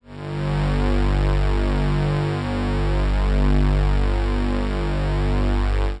G1_trance_pad_2.wav